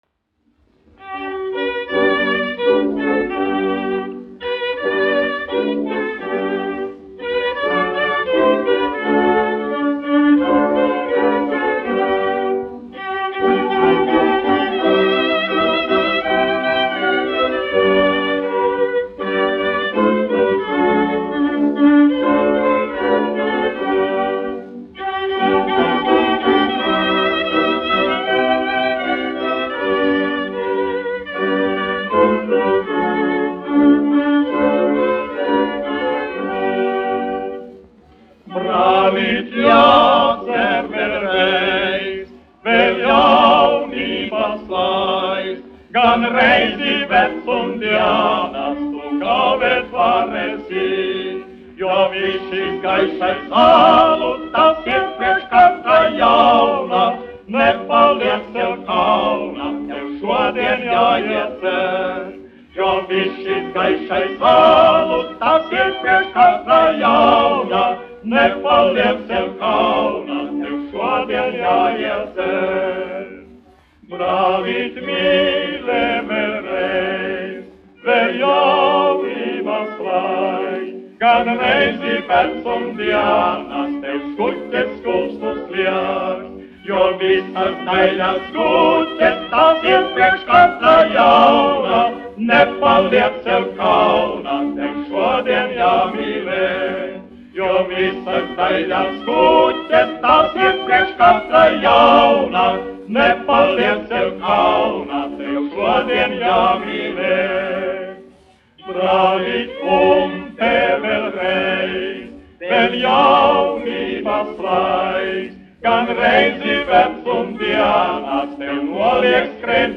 1 skpl. : analogs, 78 apgr/min, mono ; 25 cm
Vokālie kvarteti
Populārā mūzika
Latvijas vēsturiskie šellaka skaņuplašu ieraksti (Kolekcija)